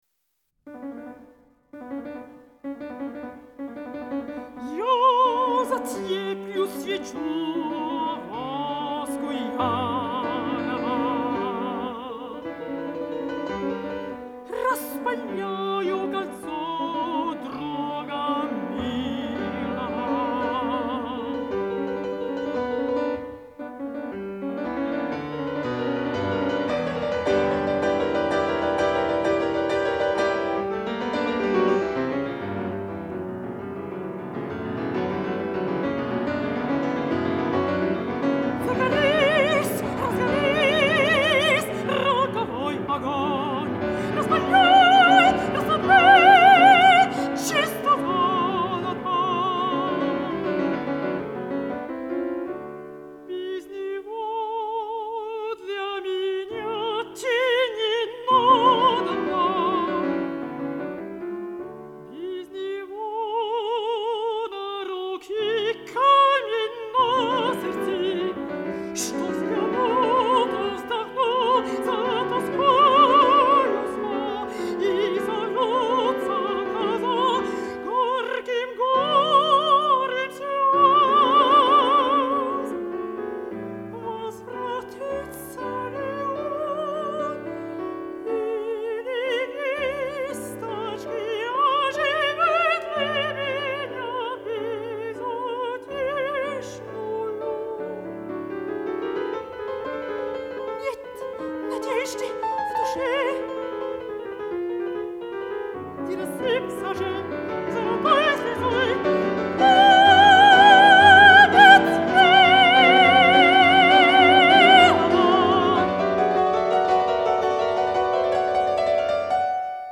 мецо сопрано
пиано